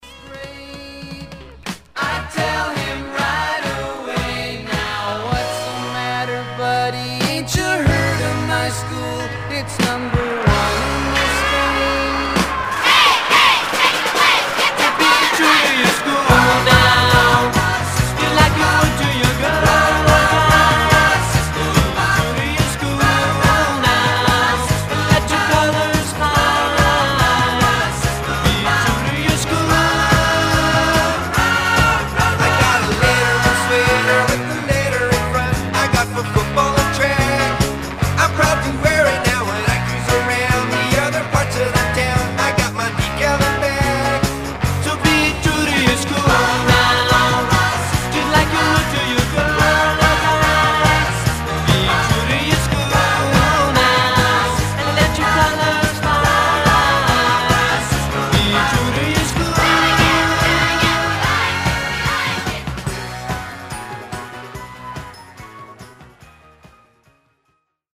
Stereo/mono Mono
Surf